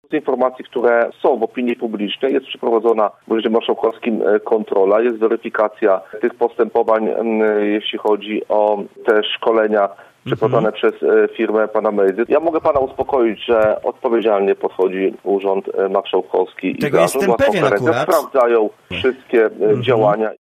Do tych działań odniósł się dziś na naszej antenie Marcin Pabierowski, zielonogórski radny Koalicji Obywatelskiej: